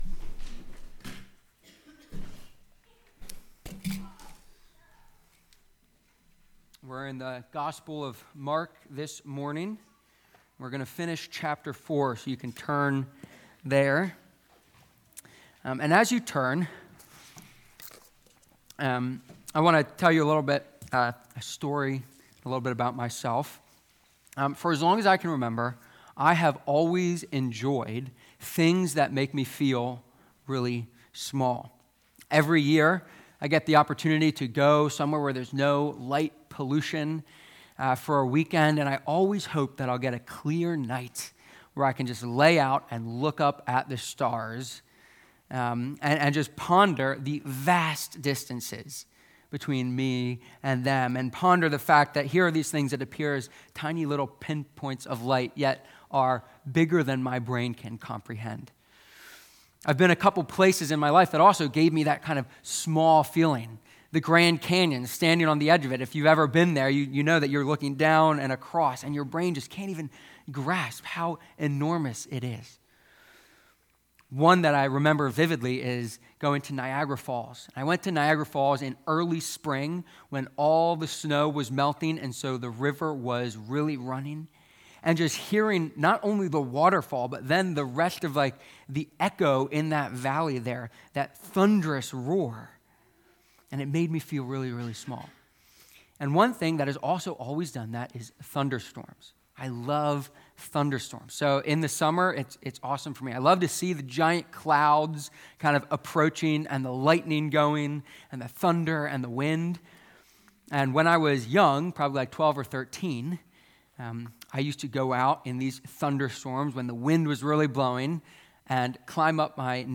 Mark-4-storm-sermon.mp3